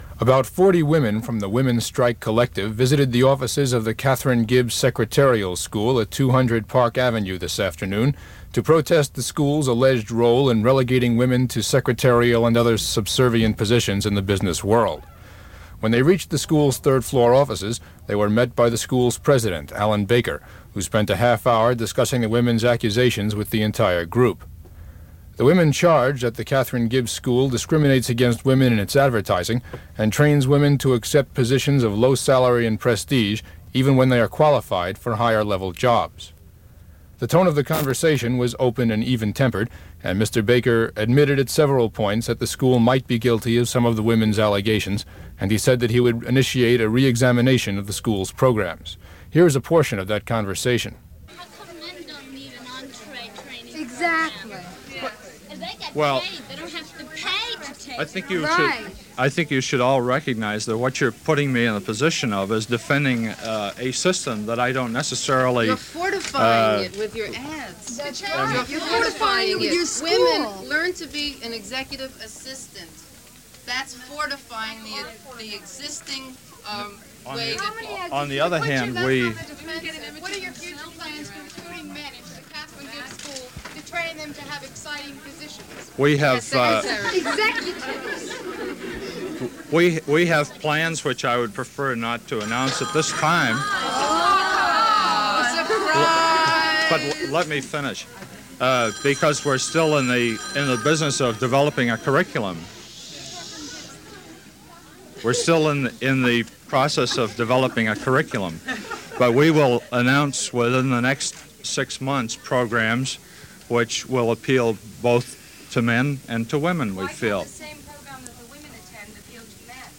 News Coverage.